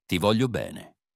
1. "Ti" = "tee": Short and crisp.
3. "Voglio" = "VOH-lyoh": The GLI makes the "LY" sound (like in "million").
4. "Bene" = "BEH-neh": Both E's are pronounced.